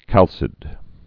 (kălsĭd)